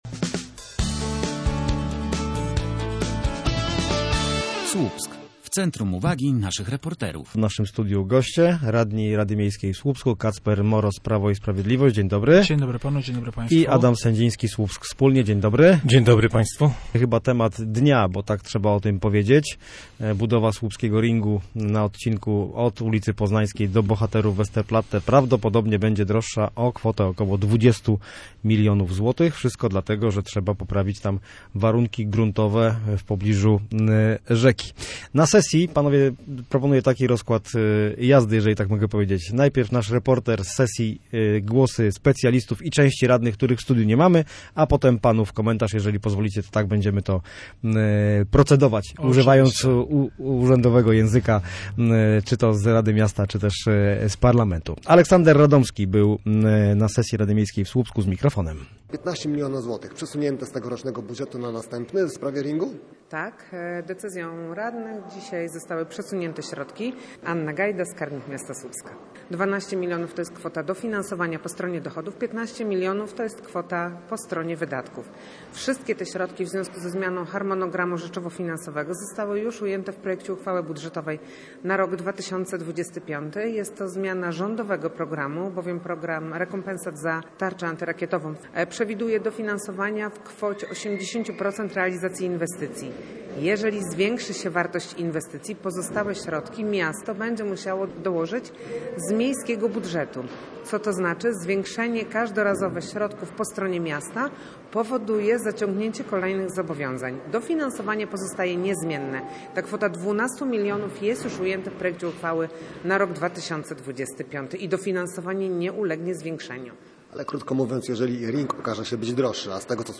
Posłuchaj dyskusji o słupskim ringu: https